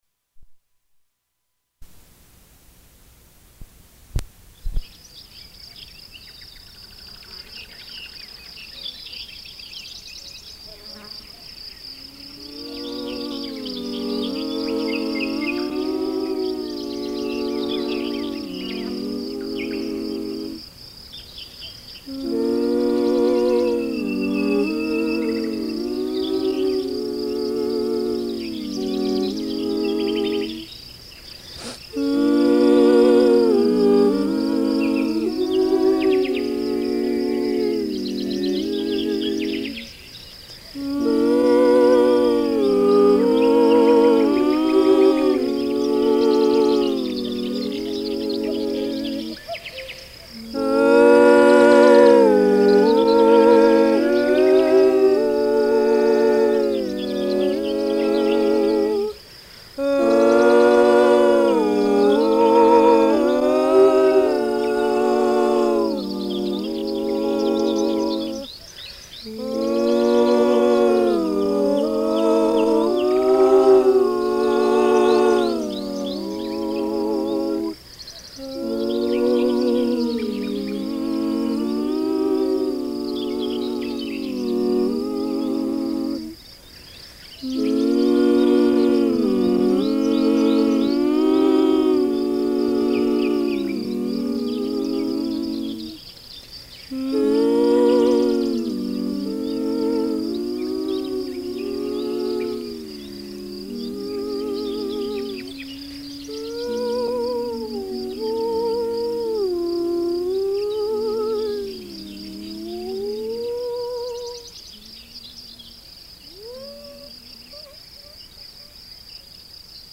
multimedia performance
US -- Illinois -- Cook -- Chicago -- Randolph Street Gallery
Cassette